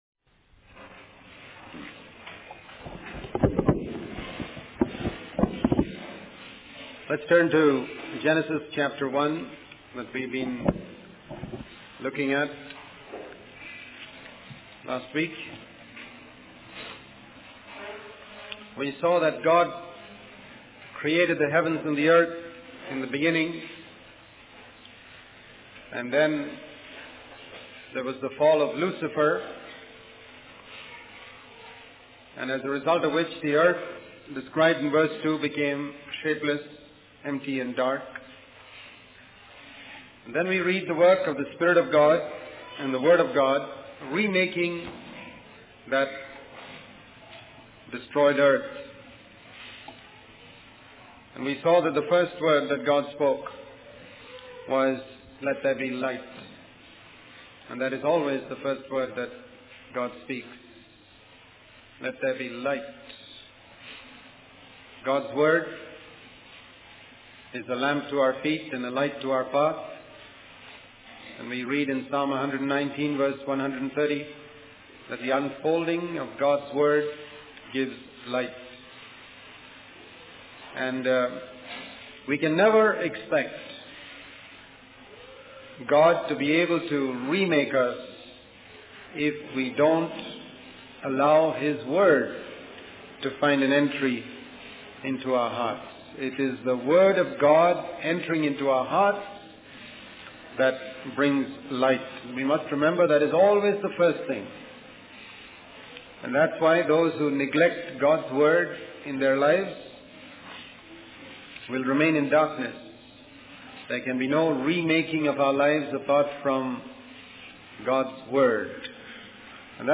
In this sermon, the speaker focuses on the importance of God's declaration that His creation is good. He points out that there is one day in the creation account where God does not say it is good, which is the end of the second day.